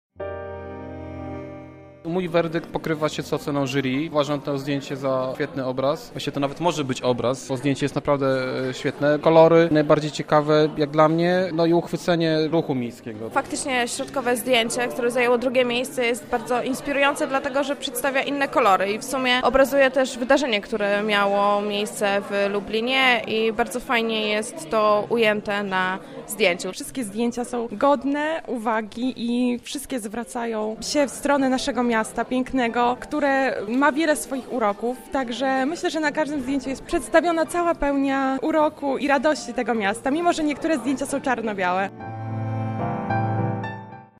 O poszczególnych pracach z uczestnikami rozmawiał nasz reporter.